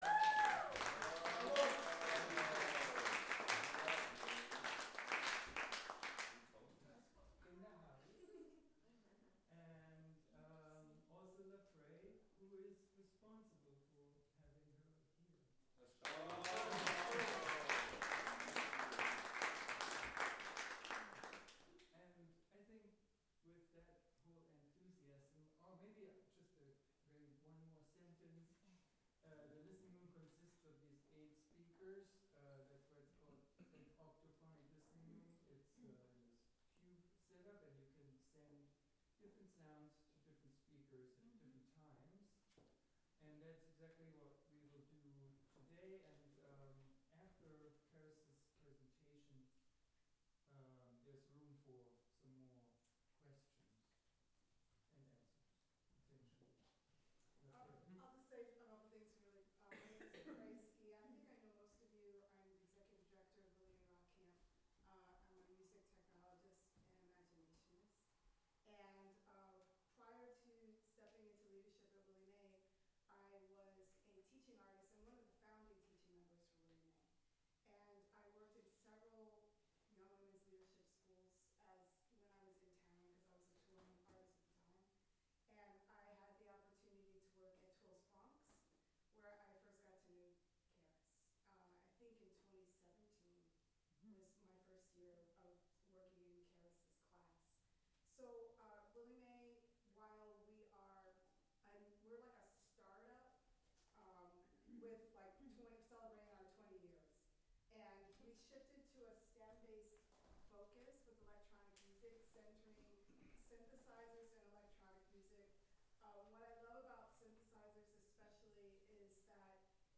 Live from Fridman Gallery: New Ear :: Spatial presents (Audio)